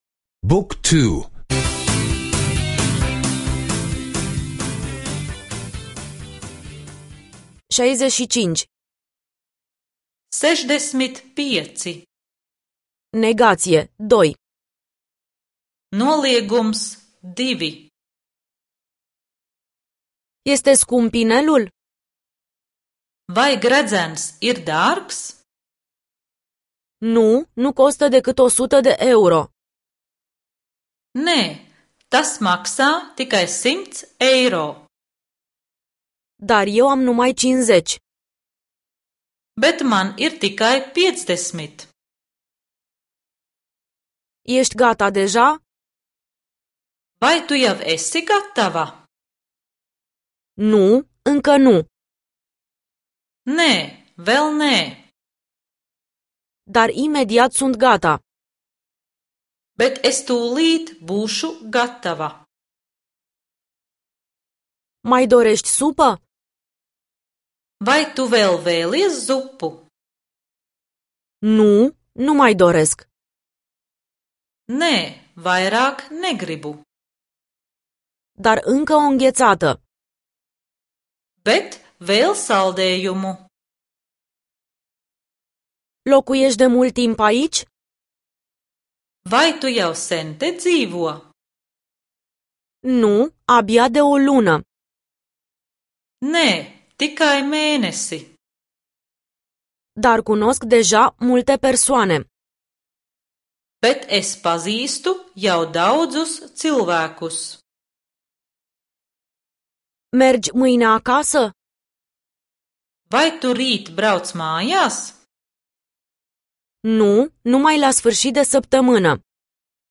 Audio lectii de limba letonă